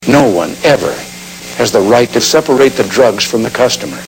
Tags: conversation uncensored funny celebrities comedy